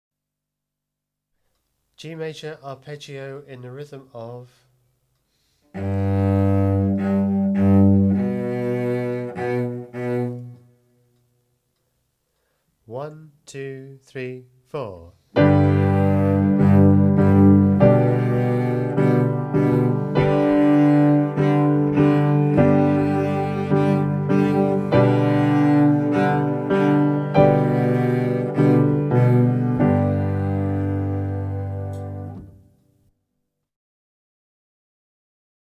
47 G-major arpeggio (Cello)